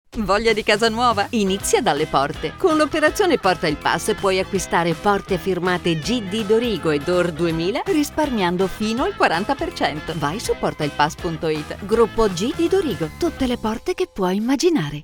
Dorigo-Spot-PortaIlPass-23-OK-radio (1).mp3